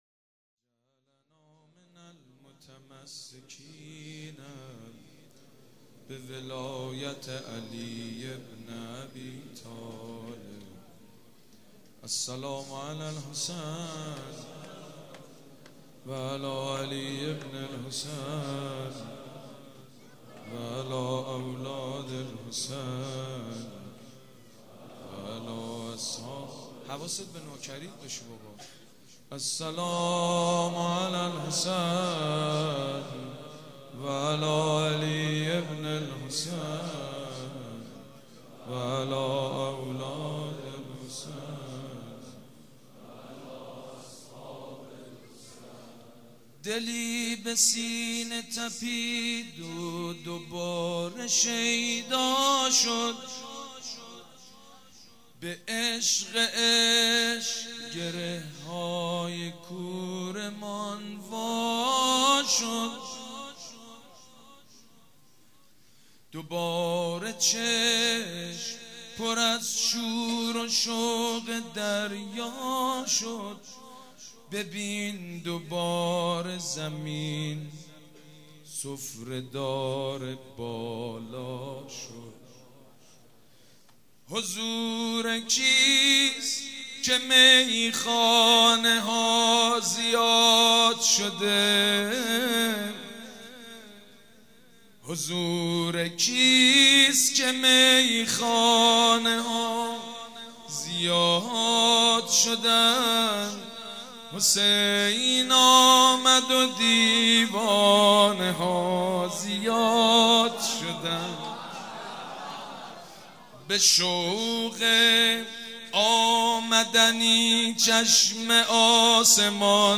مدح: دلی به سپینه تپیدو دوباره شیدا شد